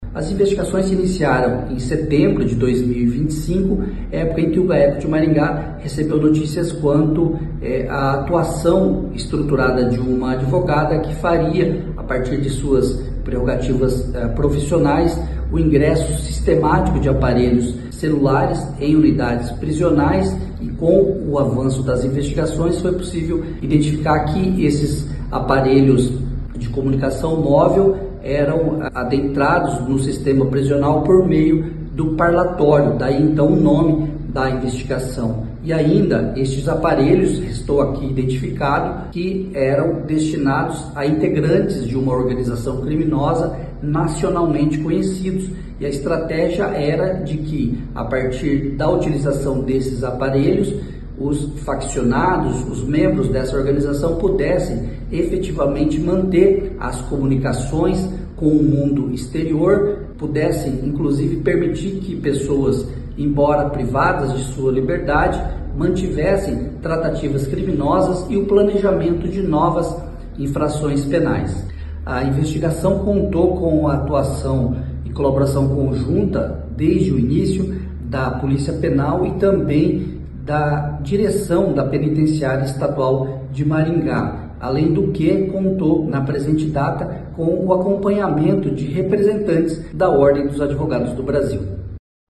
O Promotor de Justiça Marcelo Alessandro da Silva Gobbato detalha as investigações.